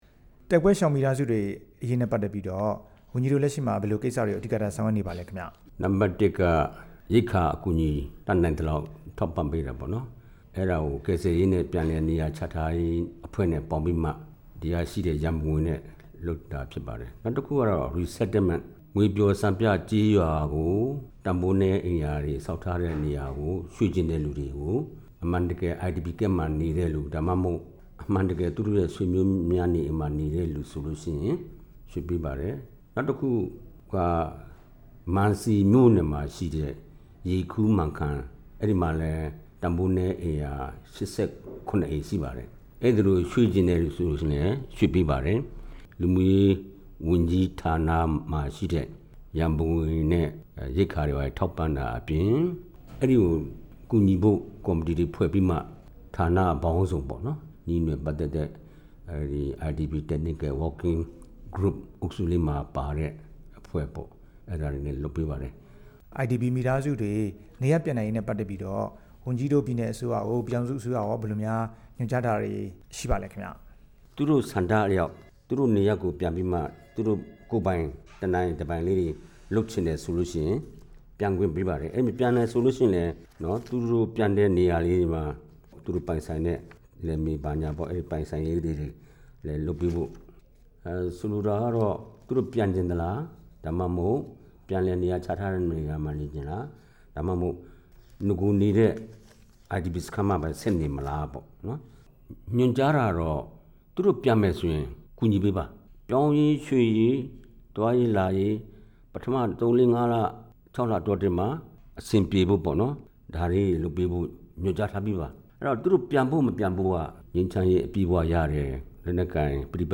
ကချင် စစ်ဘေးရှောင် ဒုက္ခသည်အရေး မေးမြန်းချက်